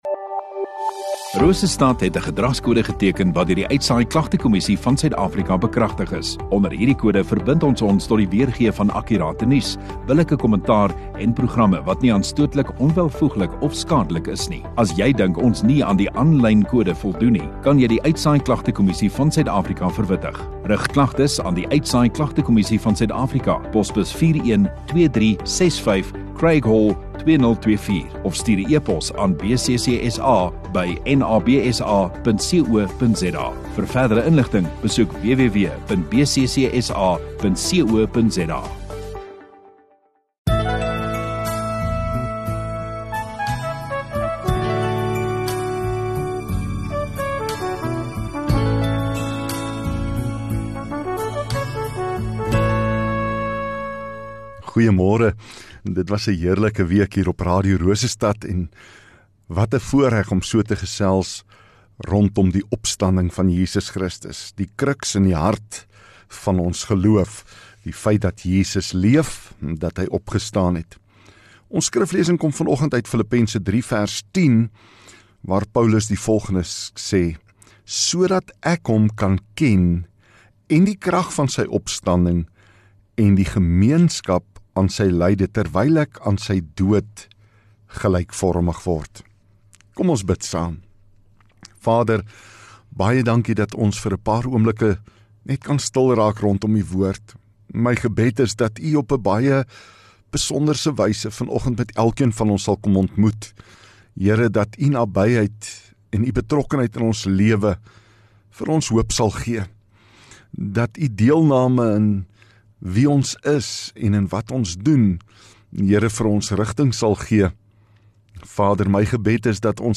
26 Apr Vrydag Oggenddiens